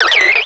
Cri de Ceribou dans Pokémon Diamant et Perle.